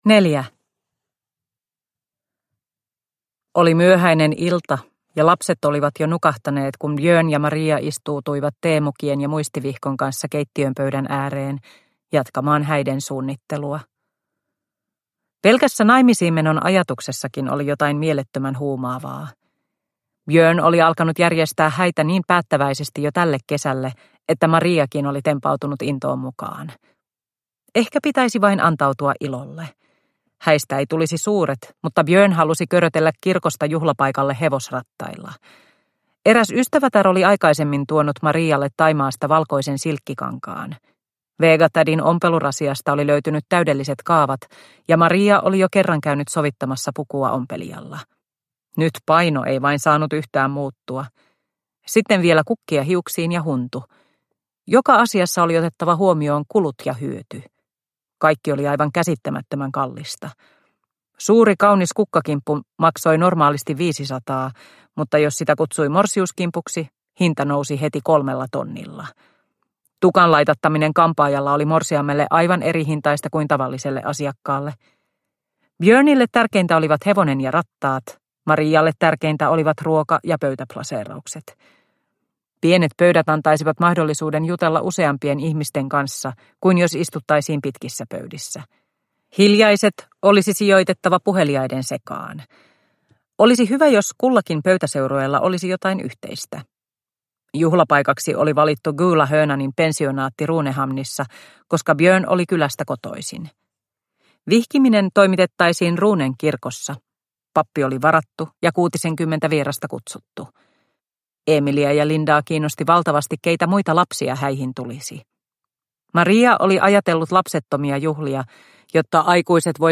Hämärän lapset – Ljudbok – Laddas ner